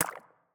SFX_Slime_Hit_01.wav